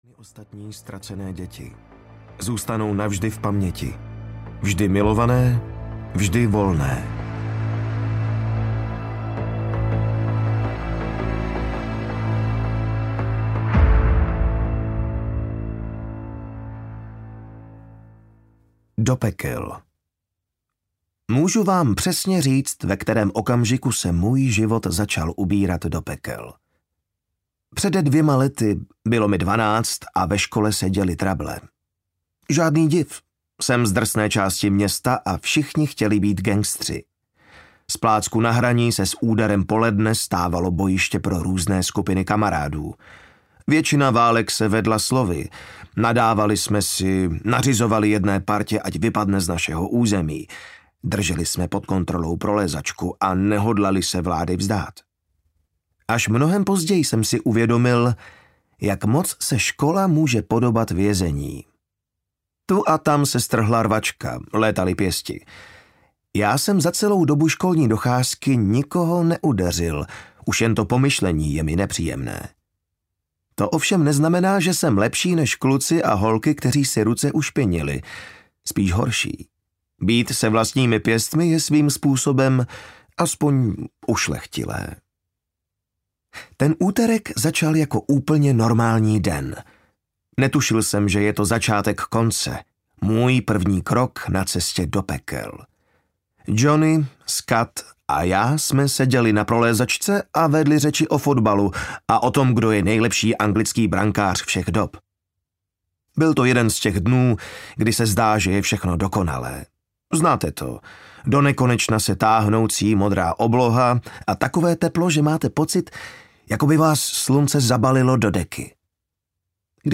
Lockdown audiokniha
Ukázka z knihy